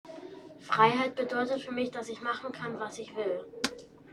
MS Wissenschaft @ Diverse Häfen
Standort war das Wechselnde Häfen in Deutschland. Der Anlass war MS Wissenschaft